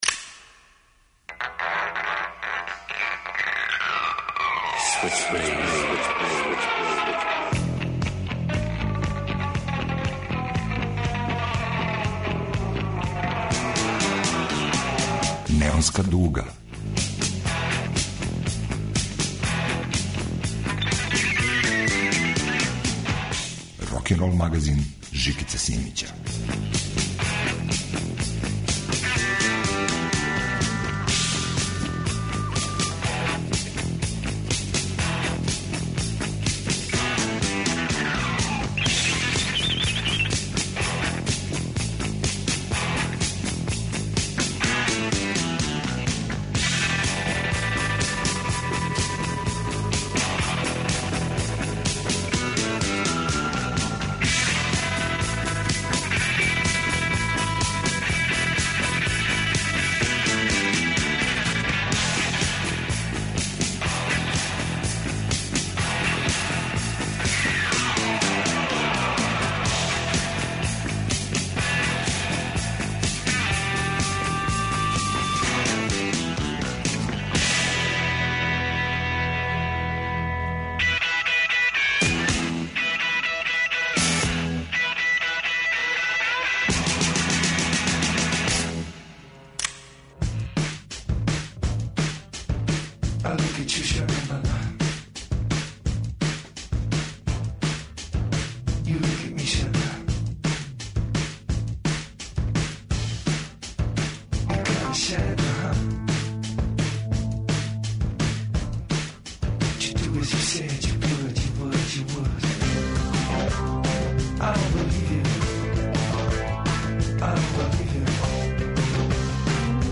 Рокенрол као музички скор за живот на дивљој страни. Вратоломни сурф кроз време и жанрове. Старо и ново у нераскидивом загрљају. Сваке недеље на Радио Београд 2 од 18.00 до 20.00 часова.